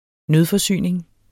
Udtale [ ˈnøð- ]